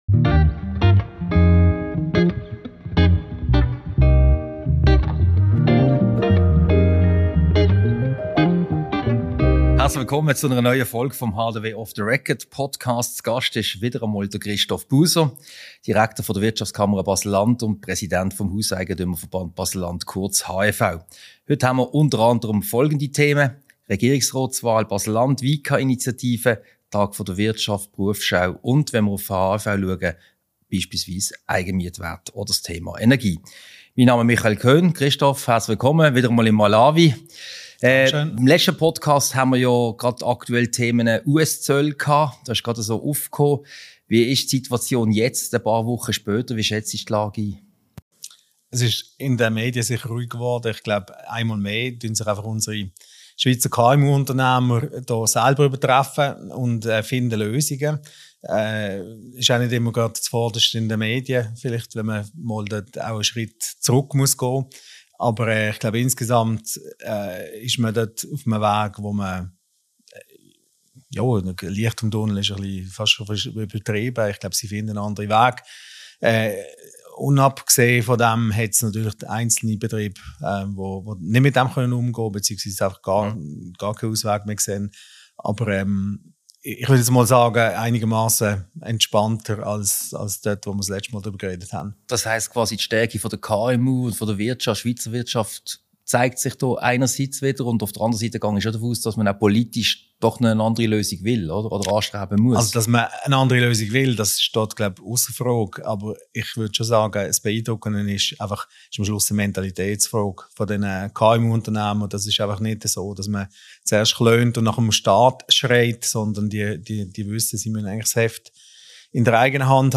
Ein Gespräch über die Initiativen der Wirtschaftskammer Baselland, die Regierungsratswahlen in Basel-Landschaft, den Stillstand bei den Strassenprojekten, die Effizienzprobleme bei der Bau- und Umweltschutzdirektion sowie andere aktuelle wirtschaftspolitische Themen. Diese Podcast-Ausgabe wurde als Video-Podcast im Sitzungszimmer Malawi im Haus der Wirtschaft HDW aufgezeichnet.